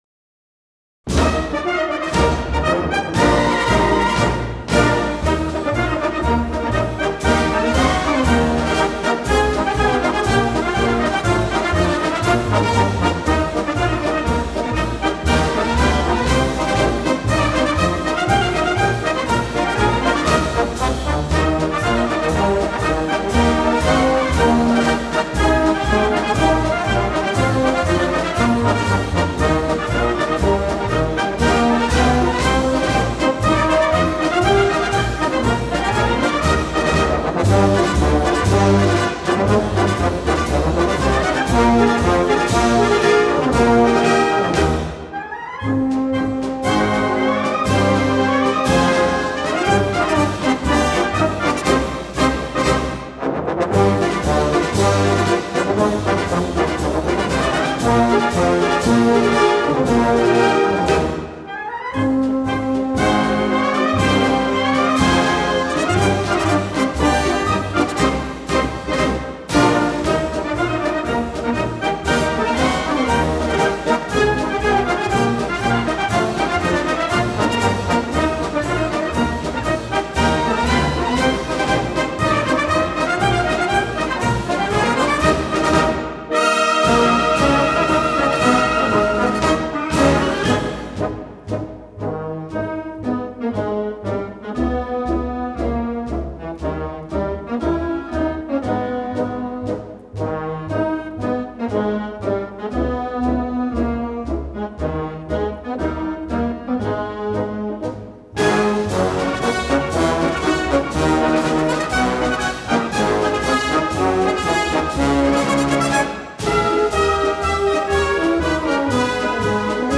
Главная / Computer & mobile / Мелодии / Марши